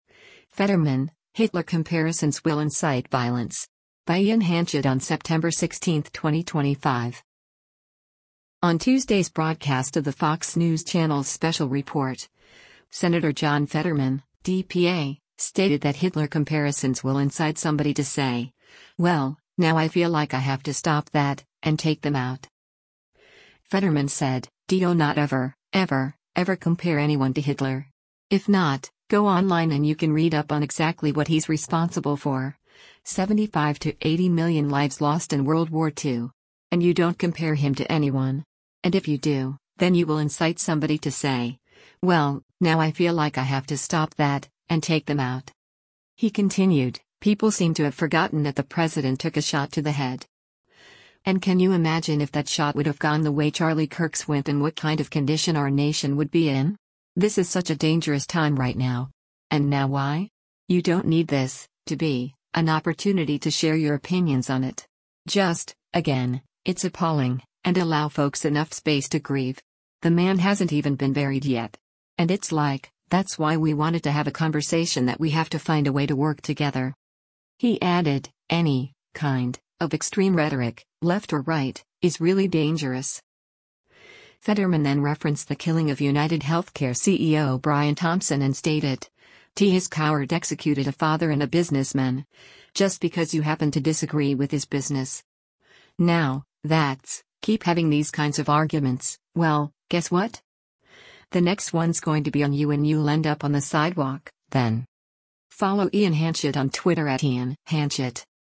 On Tuesday’s broadcast of the Fox News Channel’s “Special Report,” Sen. John Fetterman (D-PA) stated that Hitler comparisons “will incite somebody to say, well, now I feel like I have to stop that, and take them out.”